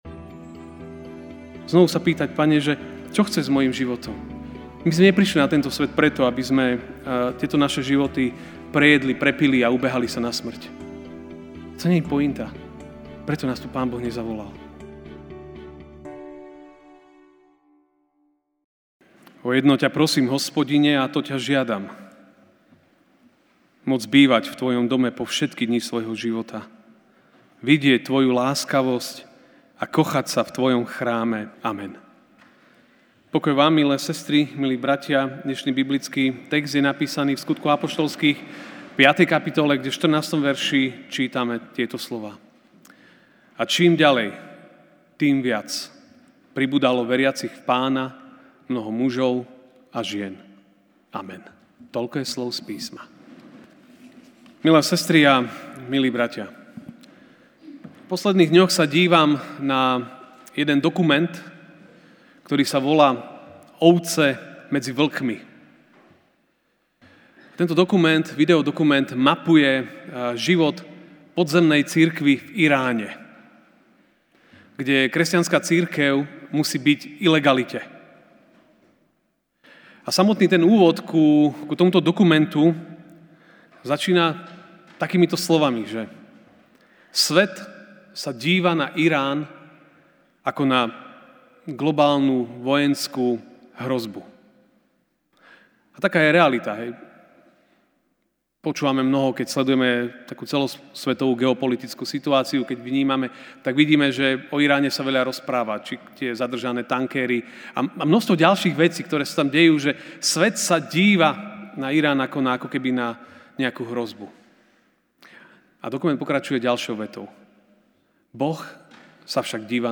sep 29, 2019 Kde Boh pôsobí, premenu spôsobí MP3 SUBSCRIBE on iTunes(Podcast) Notes Sermons in this Series Ranná kázeň: Kde Boh pôsobí, premenu spôsobí (Sk 5, 14) A čím ďalej, tým viac pribúdalo veriacich v Pána, mnoho mužov a žien.